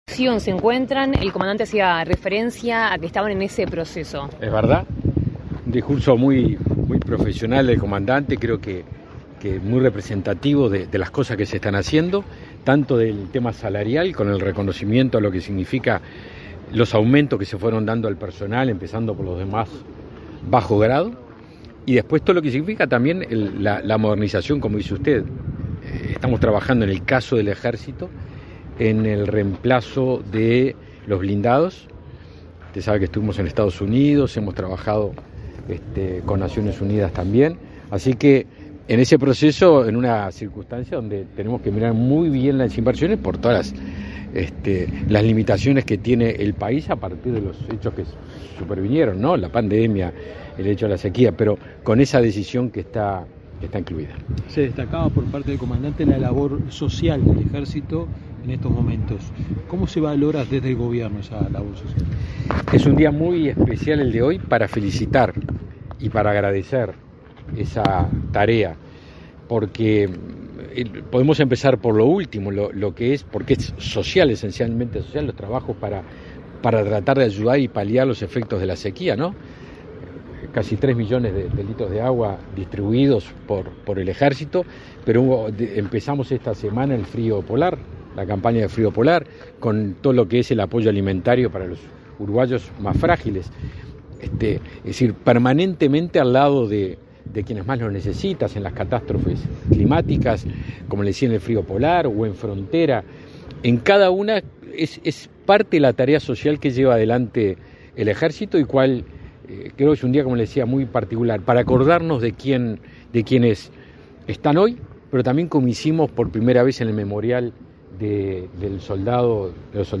Declaraciones a la prensa del ministro de Defensa Nacional, Javier García | Presidencia Uruguay
Declaraciones a la prensa del ministro de Defensa Nacional, Javier García 18/05/2023 Compartir Facebook X Copiar enlace WhatsApp LinkedIn Con la presencia del presidente de la República, Luis Lacalle Pou, se conmemoró este 18 de mayo el 212.° aniversario del Ejército Nacional. Tras el evento, el ministro de Defensa Nacional, Javier García, realizó declaraciones a la prensa.